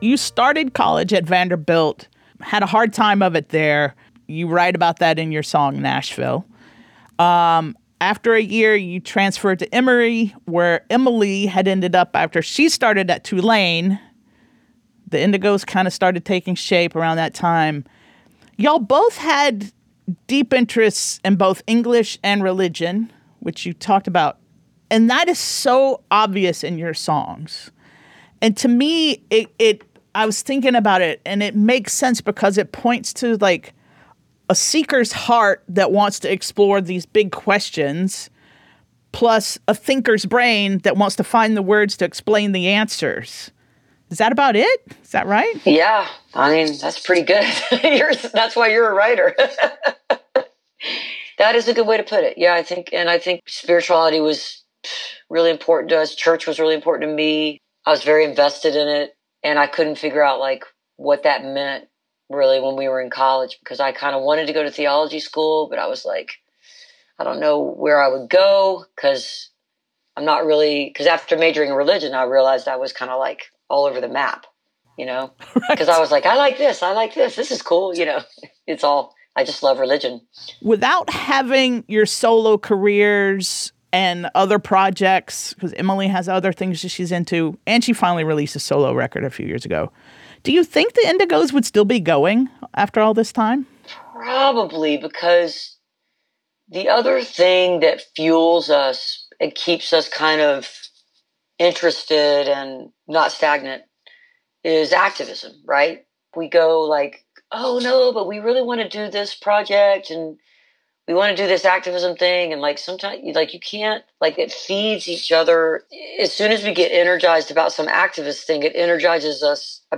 (captured from webcast)
06. interview with amy ray (2:38)